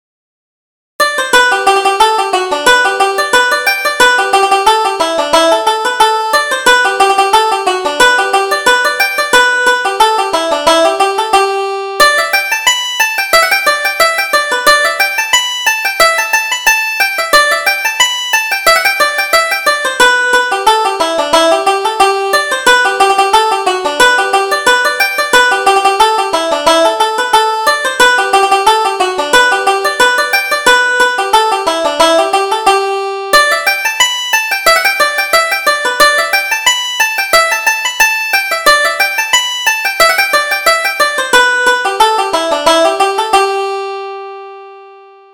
Reel: Touch Me If You Dare - 1st Setting